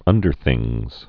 (ŭndər-thĭngz)